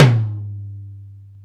TOM XTOMH0FR.wav